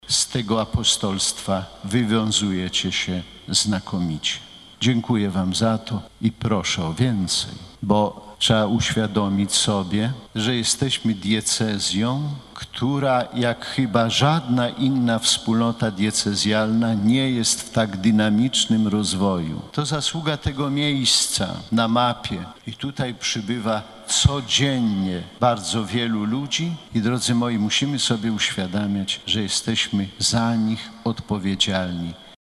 Z tej okazji bp warszawsko-praski przewodniczył uroczystej mszy św. w katedrze św. Michała Archanioła i św. Floriana Męczennika.